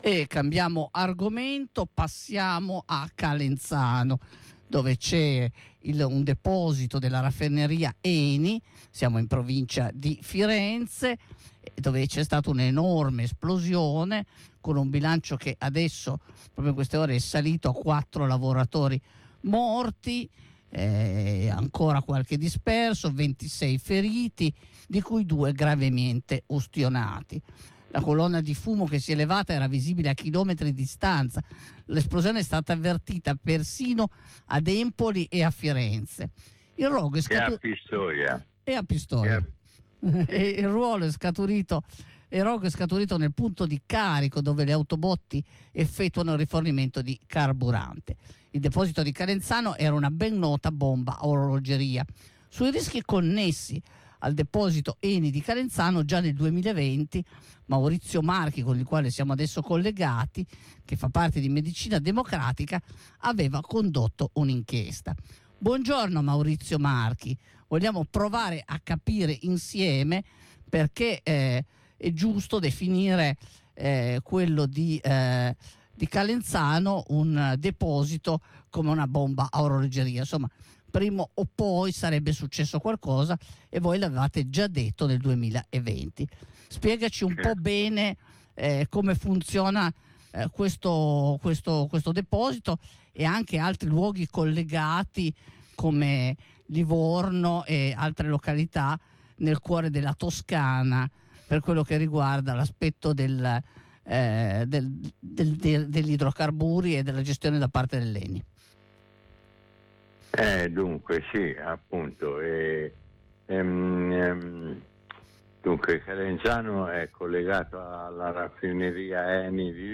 Ci siamo collegati con lui per capirne di più.